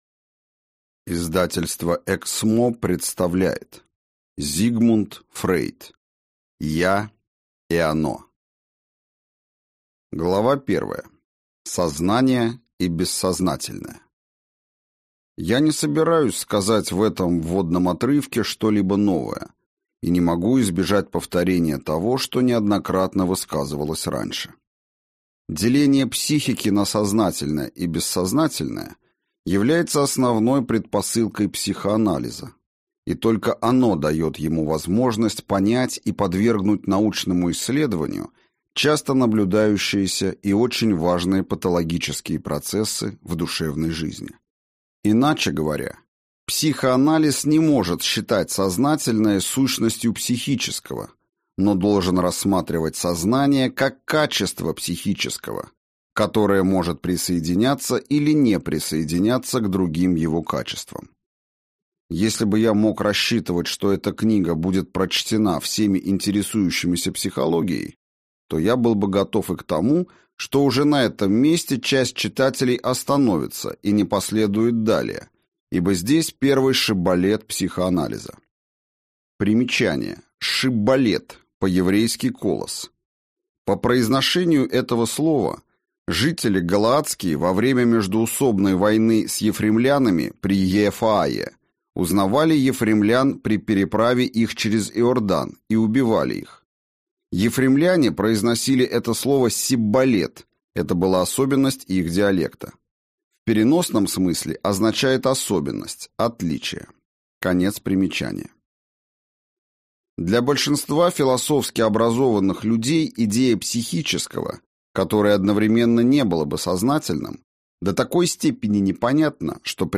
Аудиокнига Я и Оно (сборник) | Библиотека аудиокниг